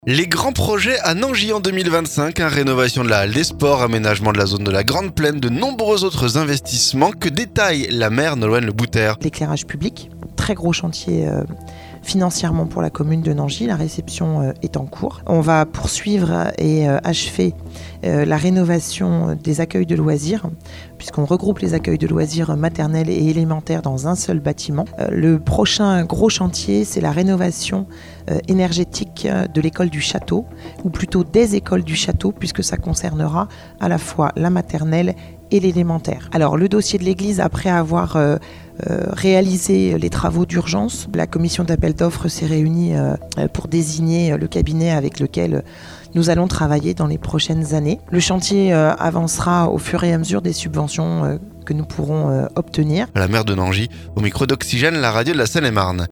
Rénovation de la halle des sports, aménagement de la zone de la Grande Plaine, et de nombreux autres investissements que détaille la maire, Nolwenn Le Bouter.